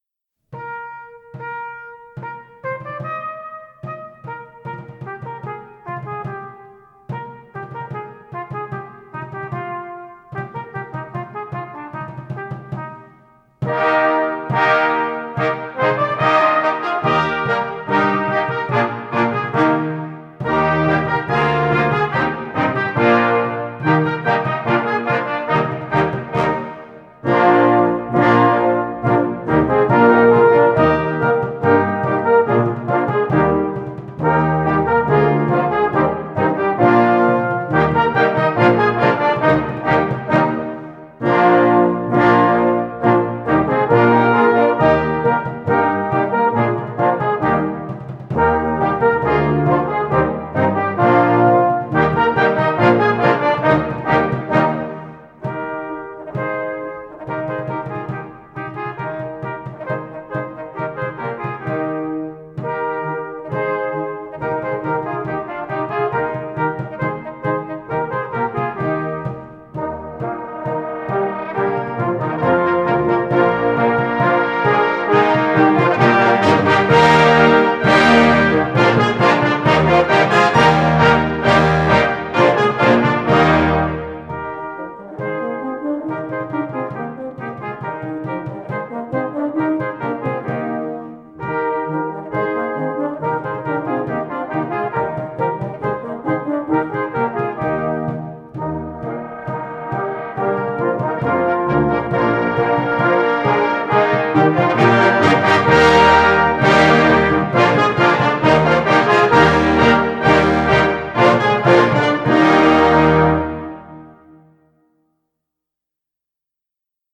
Brass Band Berner Oberland: Alter Berner Marsch.
Trad. / Arr. Oliver Waespi